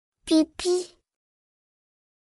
\Pee-Pee\